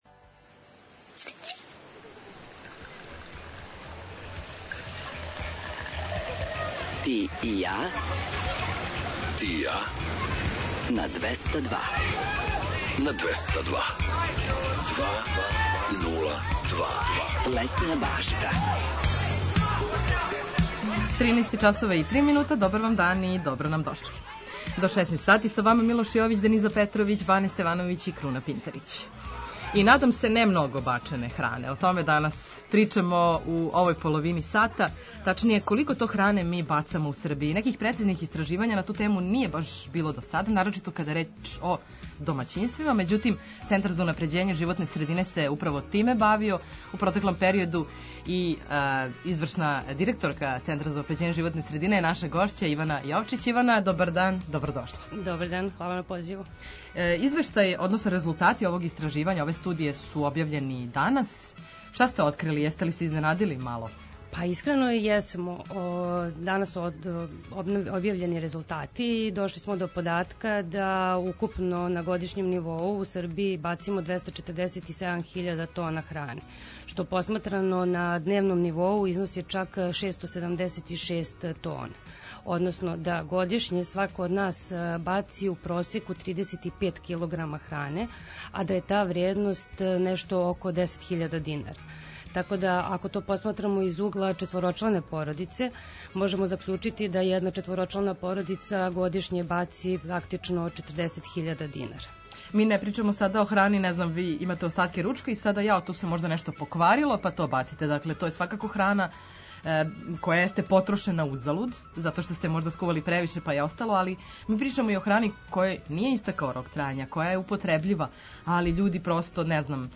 У наставку емисије очекује вас више музике, приче о песмама, важним албумима, рођенданима музичара, а завирићемо и у највеће хитове светских топ листа. Обрадоваће вас и пола сата „домаћица”, песама из Србије и региона.